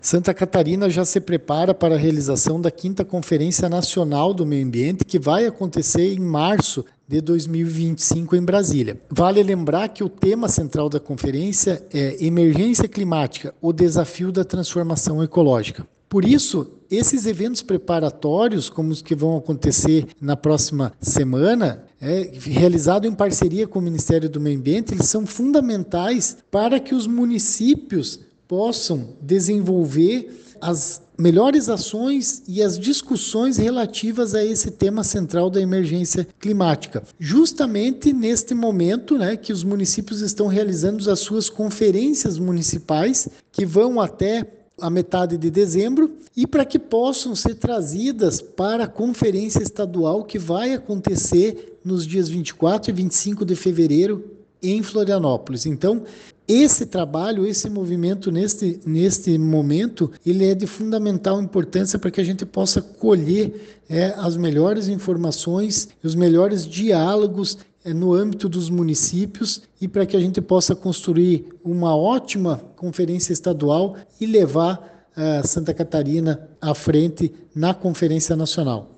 Durante o encontro, os participantes terão a oportunidade de discutir propostas para aprimorar e fortalecer as políticas públicas ambientais, que serão compiladas em um relatório final com recomendações a serem enviadas para a 5ª Conferência Nacional do Meio Ambiente, como ressalta o secretário de Estado do Meio Ambiente e da Economia Verde de Santa Catarina, Guilherme Dallacosta: